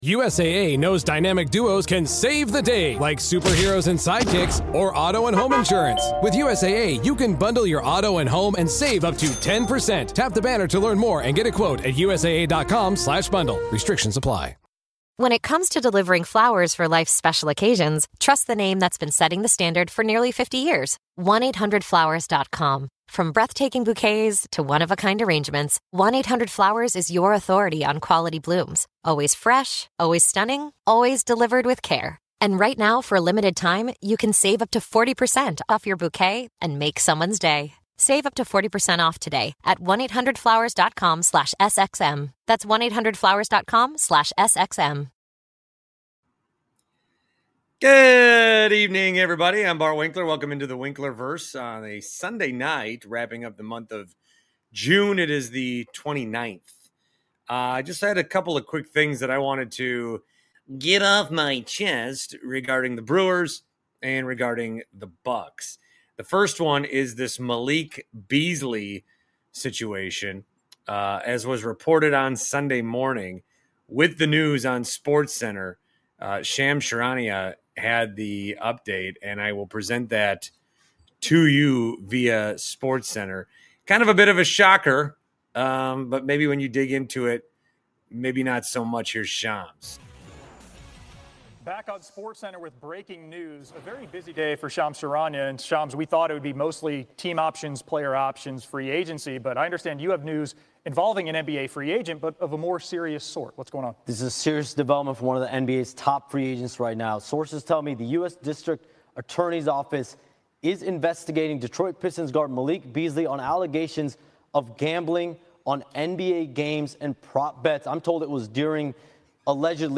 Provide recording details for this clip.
goes live on a Sunday night with some old friends